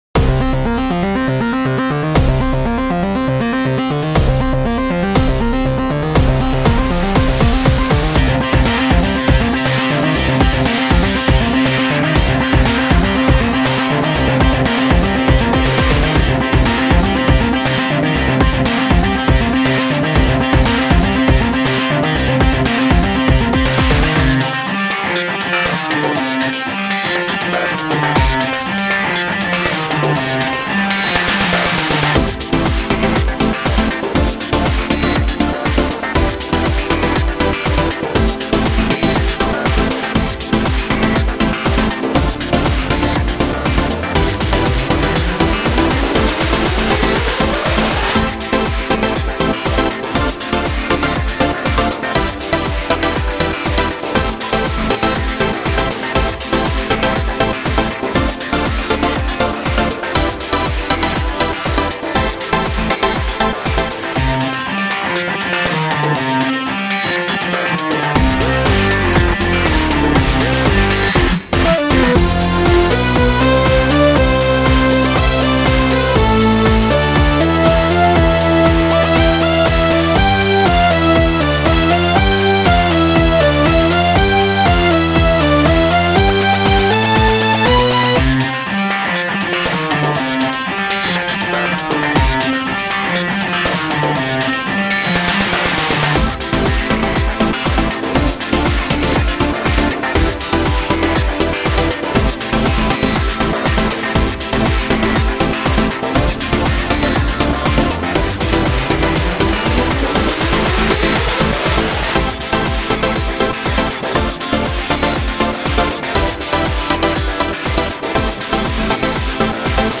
まるでネオンの光が空気に溶けていくみたいなサウンドです。
曲は全体的に中速テンポ、構成もシンプル。でもアルペジオとパッドをレイヤーして空間を作り、浮遊感を演出しています。
→ ループ対応OGG（ループポイント設定済）